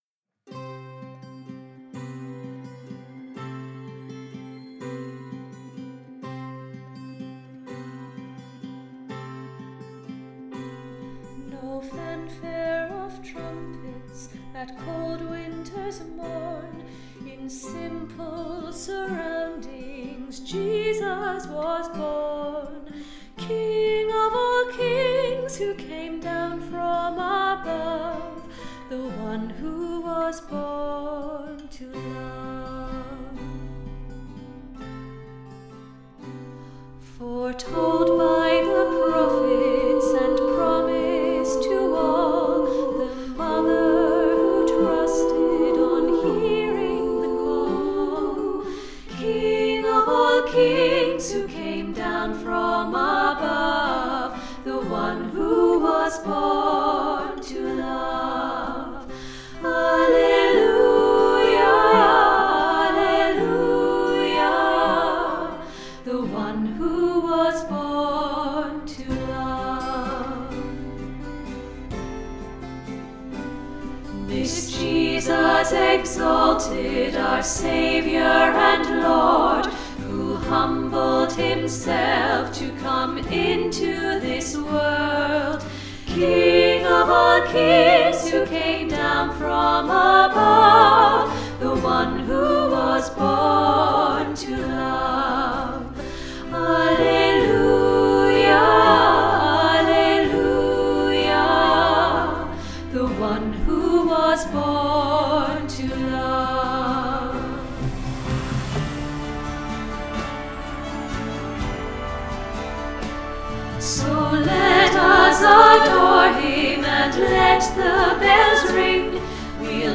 The One Who Was Born To Love SATB